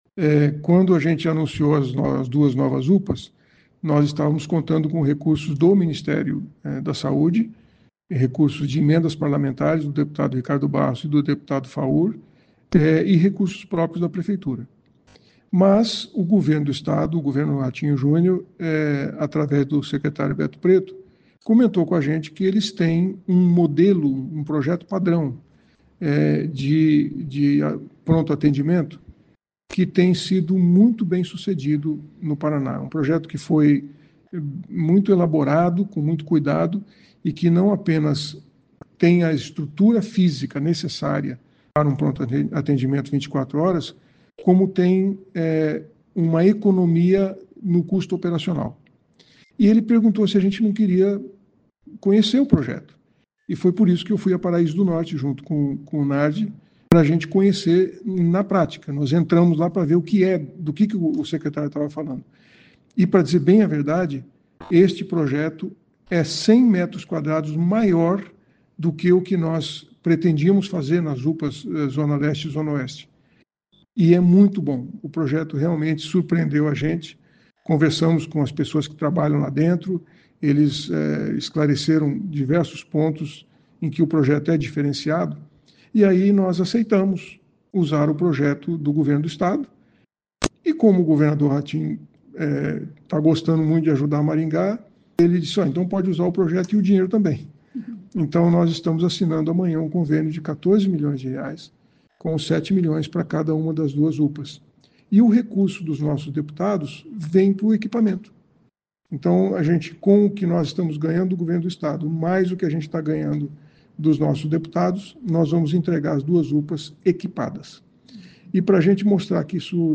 Essas unidades serão construídas na zona oeste e na zona leste de Maringá e a exemplo de uma UPA, irão atender 24h. O prefeito Silvio Barros explica que o modelo do Estado vai substituir as UPAs anunciadas pela administração.